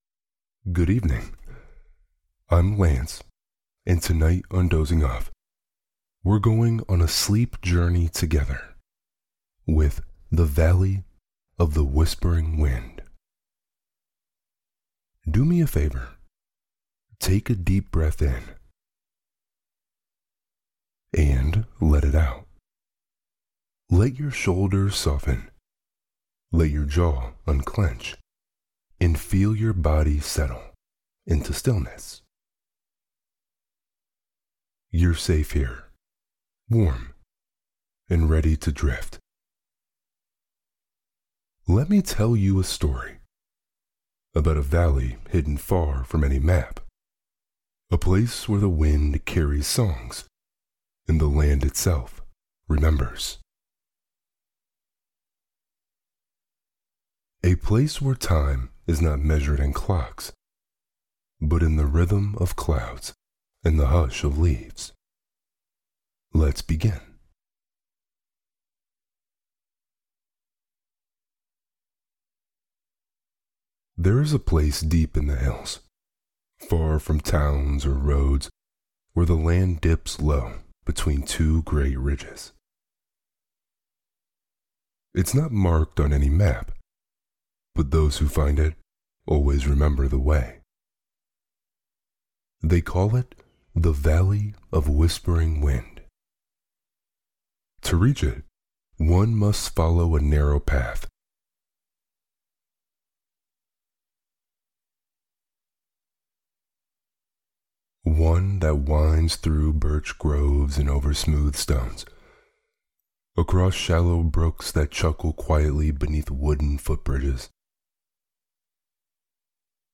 Winnie The Pooh - A.A. Milne (Chapter 4&5) – Dozing Off | Deep Voice ASMR Bedtime Stories – Lyssna här